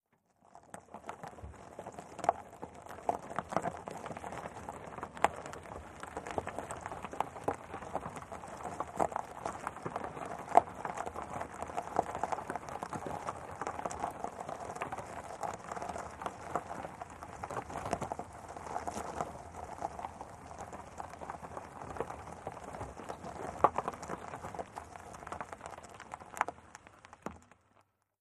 Tires Rolling On Gravel, Cu, Slow.